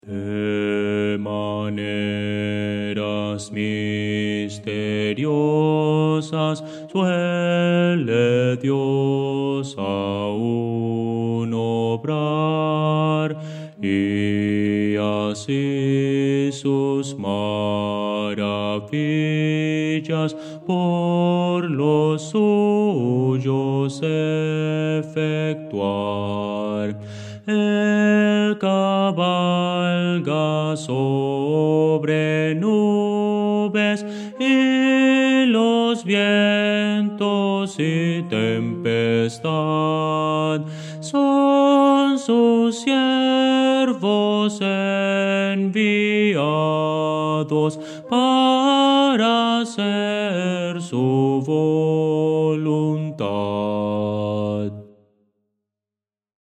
Bajo – Descargar